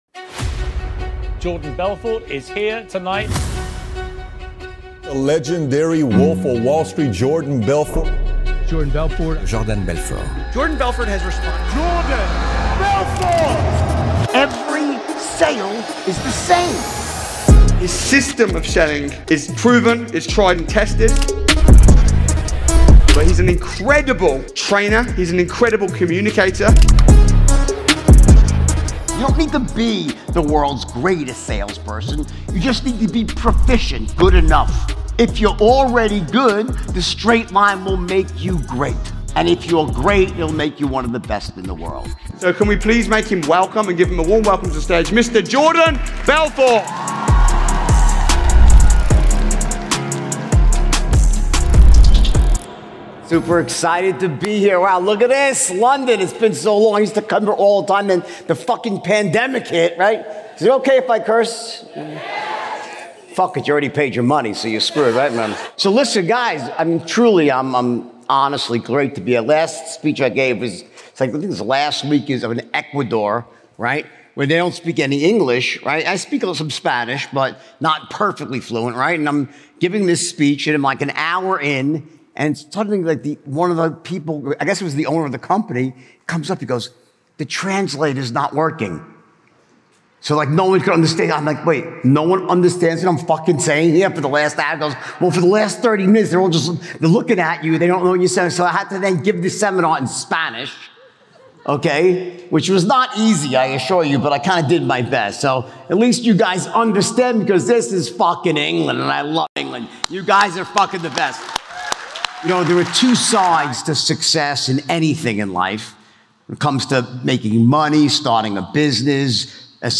30 Years of Sales Knowledge in 90 Minutes | Jordan Belfort LIVE Sales Training